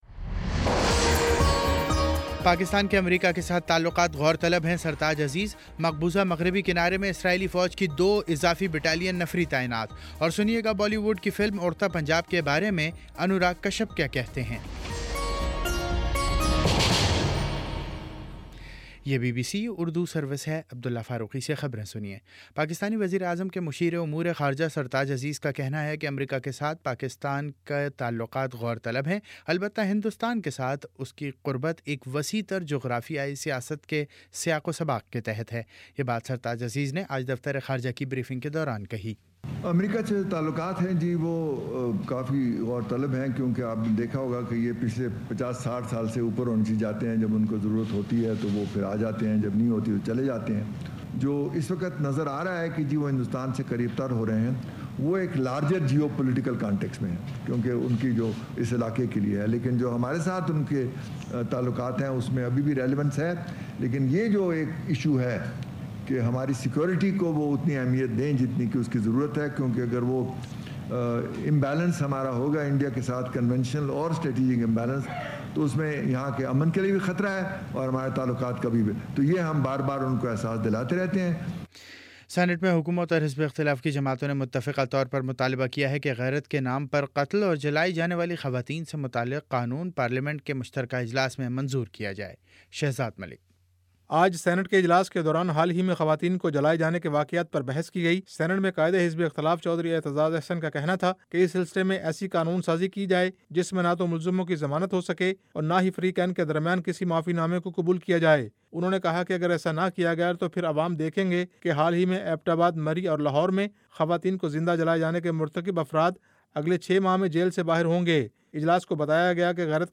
جون 09 : شام چھ بجے کا نیوز بُلیٹن